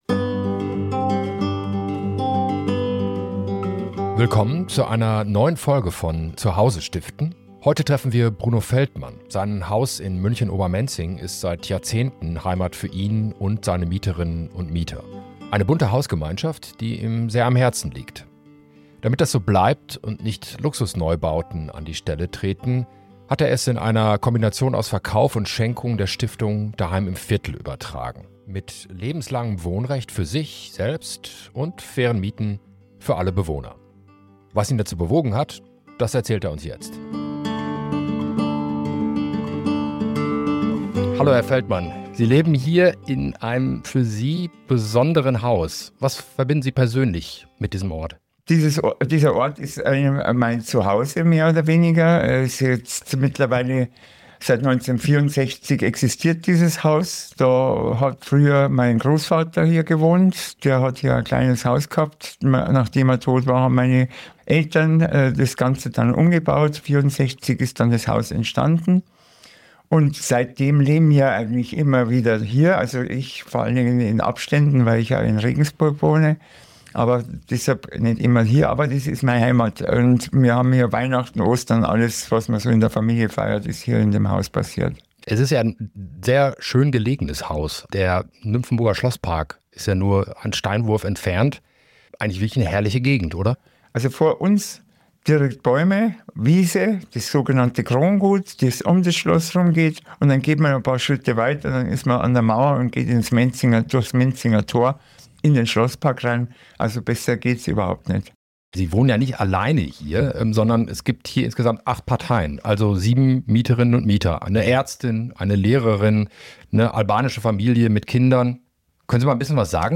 Warum er es bewusst nicht am freien Markt verkauft hat, welche Sorgen ihn umtrieben und wie die Stiftung ihm Sicherheit gibt – eine Unterhaltung über Verantwortung, Gemeinschaft und den Wert bezahlbarer Mieten.